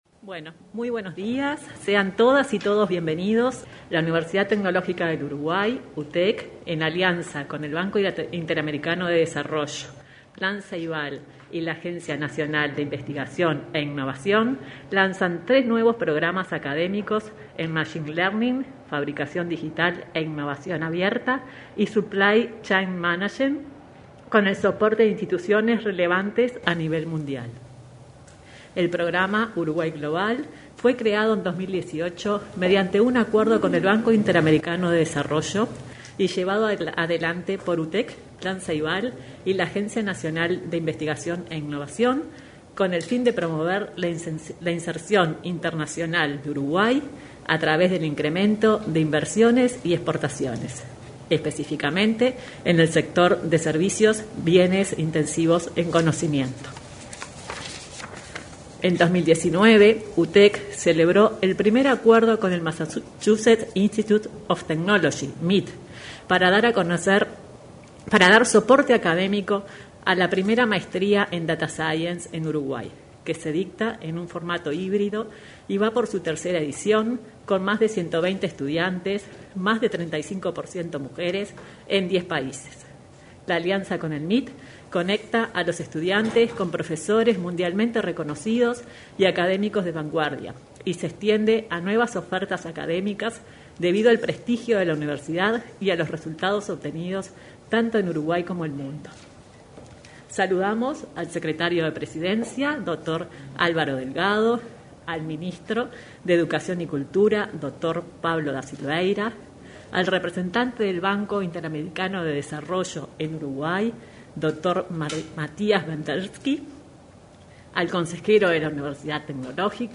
Conferencia de lanzamiento de los nuevos programas académicos de Uruguay Global